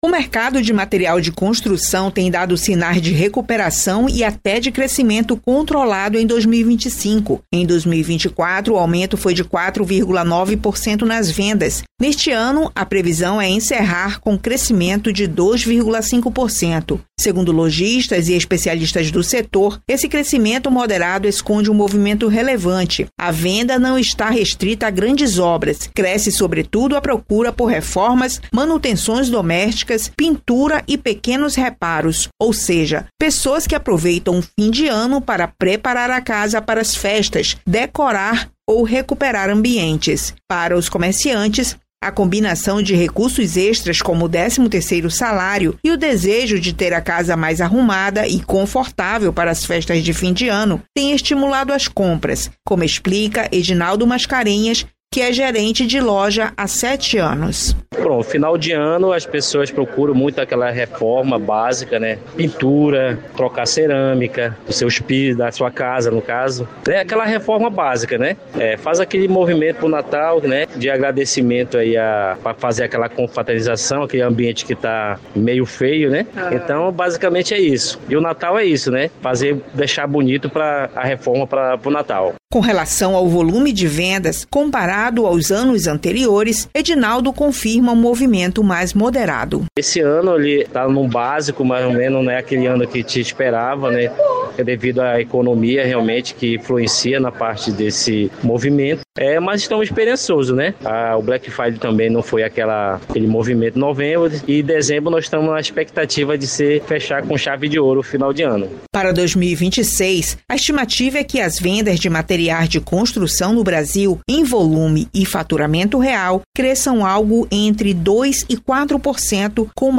Na entrevista coletiva desta quinta-feira (10), a única concedida pela seleção feminina de futebol antes do amistoso contra a Rússia, a técnica Pia Sundhage foi questionada sobre a denúncia de assédio sexual cometido pelo presidente afastado da CBF, Rogério Caboclo.